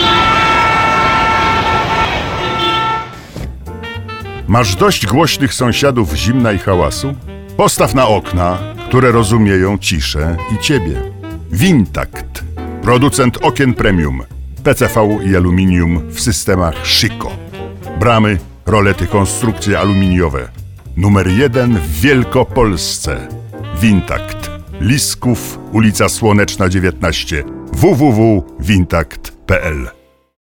Celebrity Male 50 lat +
A voice that everyone recognizes.
Spot reklamowy producenta stolarki okiennej
wintakt_wiktor_zborowski.mp3